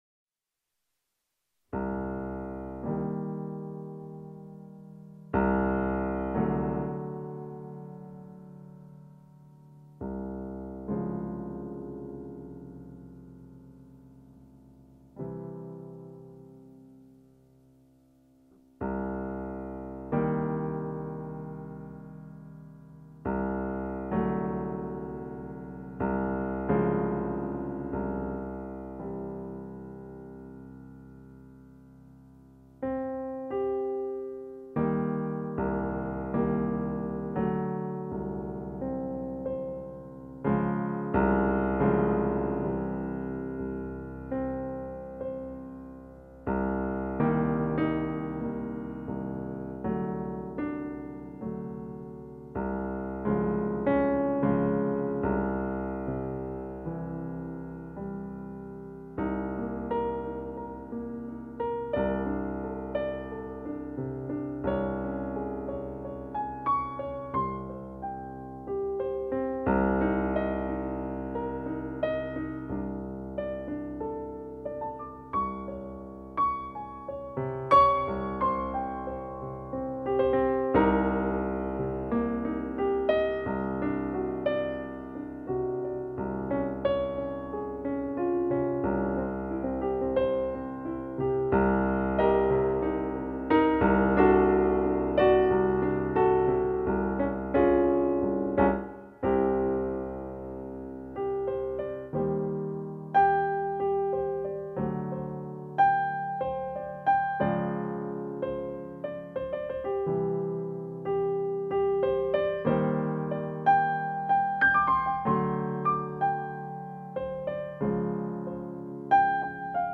Harnessing Nature’s Energy in Music | Live Music Session #16